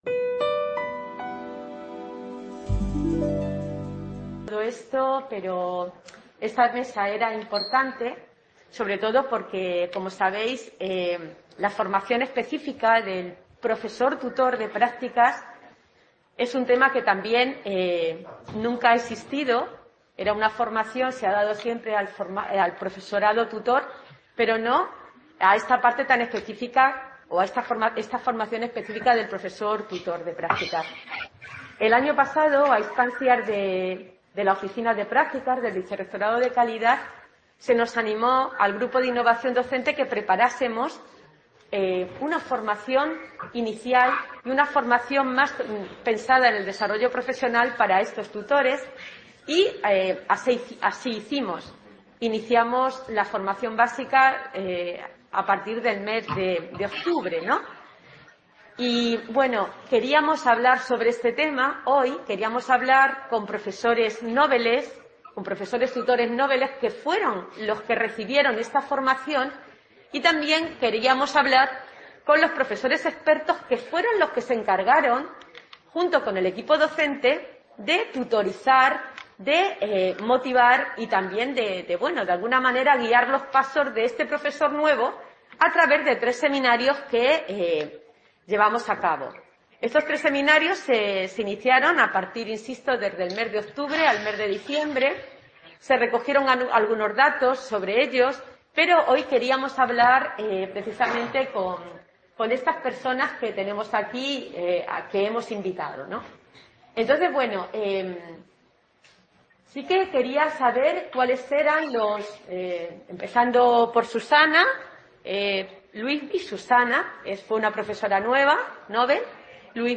CA Cantabria - II Jornadas de Visibilización de las Prácticas Profesionales. La supervisión de las prácticas en el Centro Asociado: el papel del profesor tutor de la UNED.